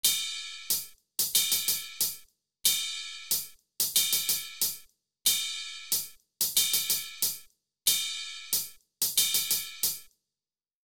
Hihat & cymbal.wav